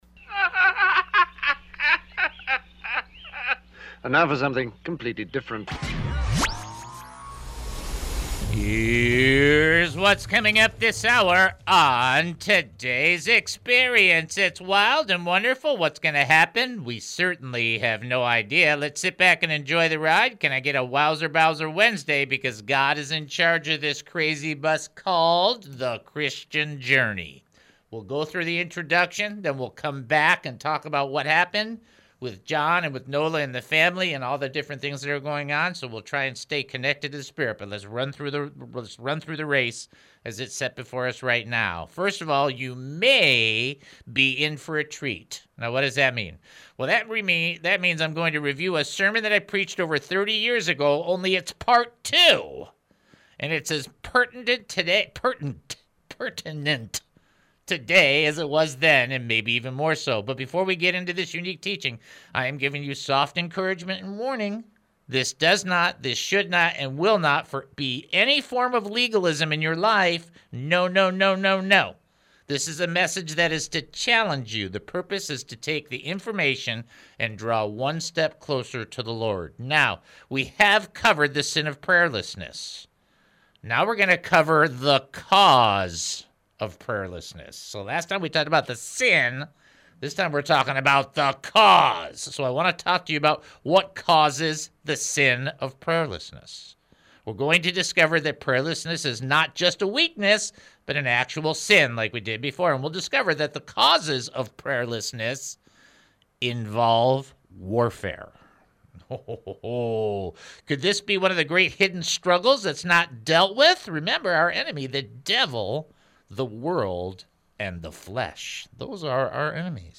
It proves to be a challenging lesson but an essential one. He emphasizes that we often devote excessive time to gratifying our worldly desires and succumbing to our old selves.